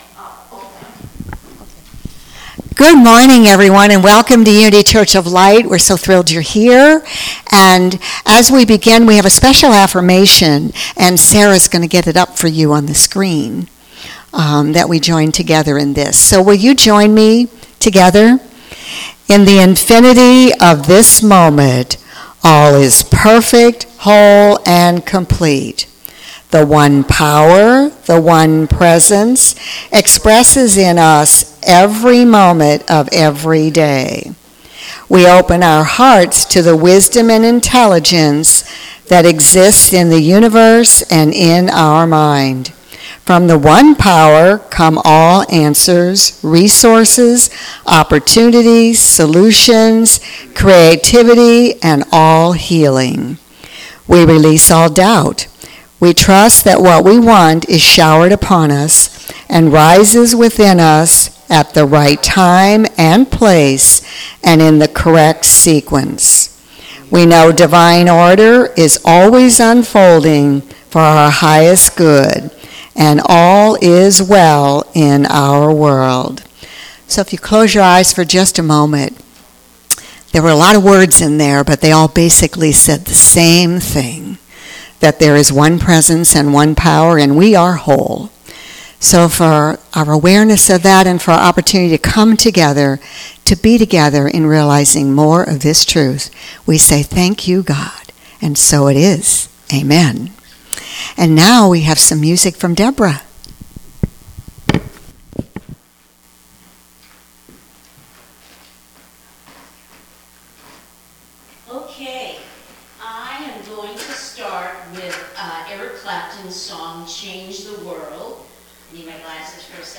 Series: Sermons 2023